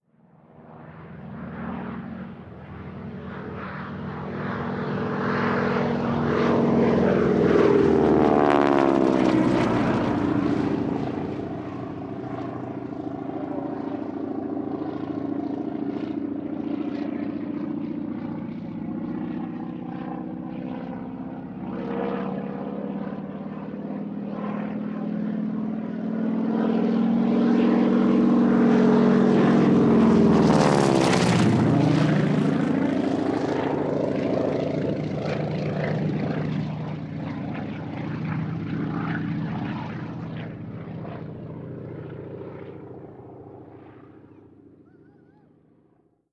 Avión T6 pasando varias veces